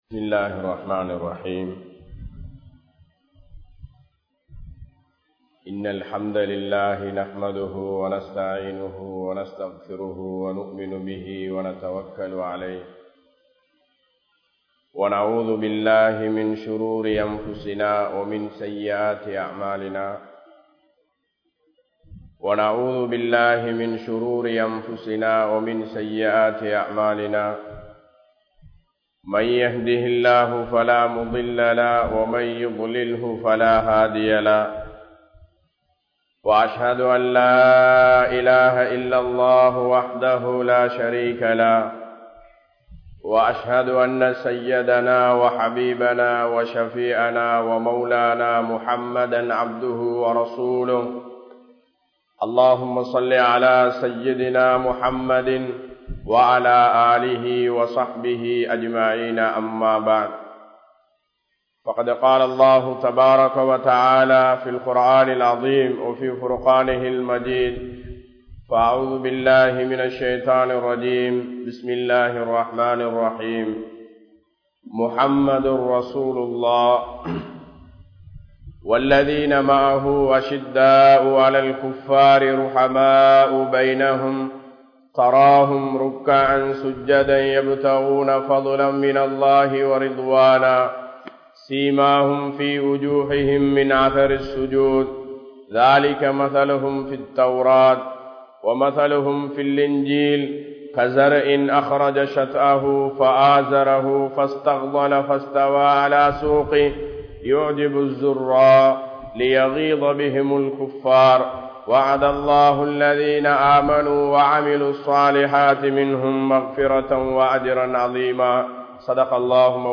Near Vali Enpathu Ethu? (நேர்வழி என்பது எது?) | Audio Bayans | All Ceylon Muslim Youth Community | Addalaichenai
Panadura, Gorakana Jumuah Masjith